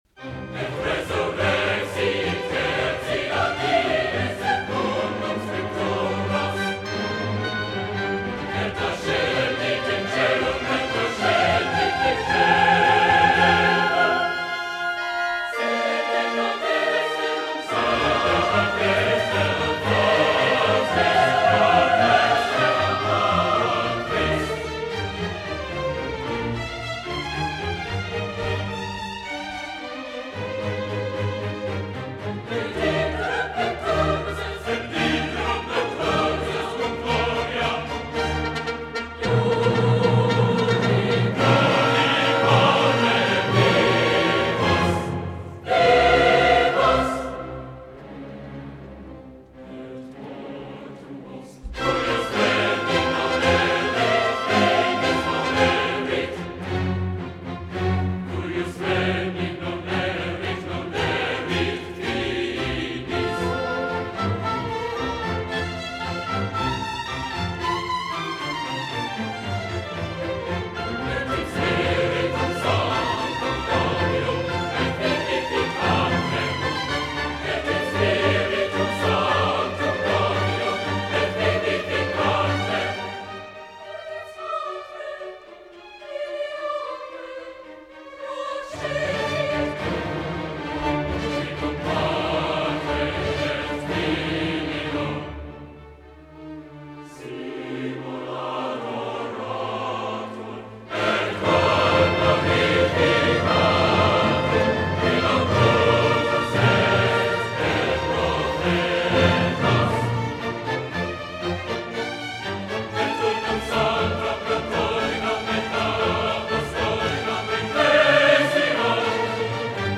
Et voilà le dimanche de Pâques, la Résurrection, avec cet extrait de Harmoniemessde Joseph Haydn, solistes, chœurs et orchestre placés sous la direction de Leonard Bernstein :